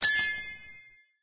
getgold1.mp3